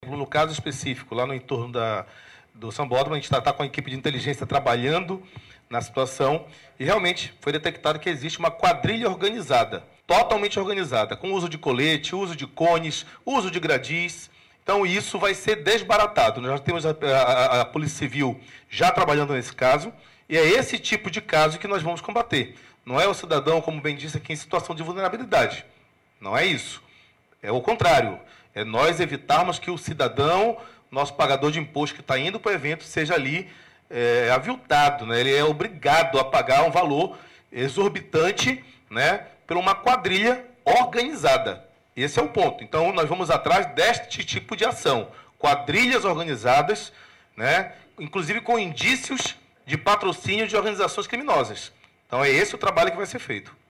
A declaração foi feita nesta quarta-feira (29) durante coletiva de imprensa no Centro Integrado de Comando e Controle (CICC), onde foram anunciadas medidas para coibir a prática.